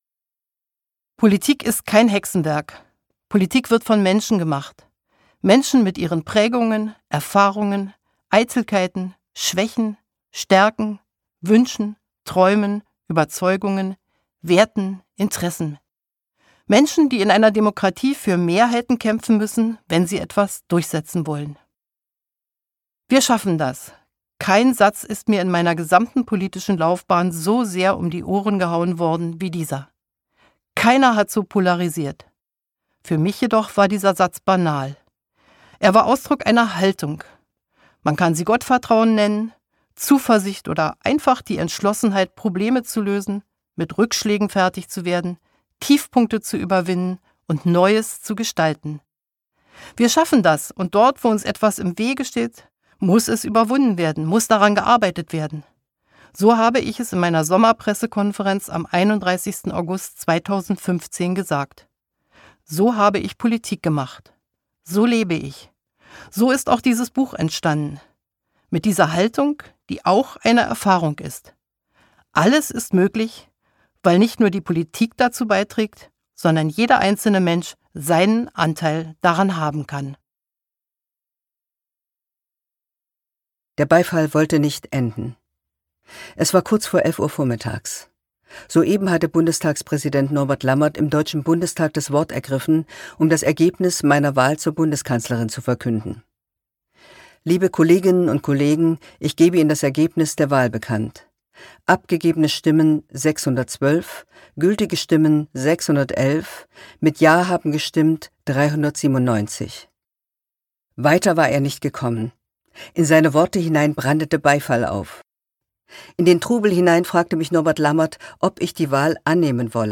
Freiheit Erinnerungen 1954-2021 Angela Merkel , Beate Baumann (Autoren) Corinna Harfouch (Sprecher) Audio Disc 2024 | 1.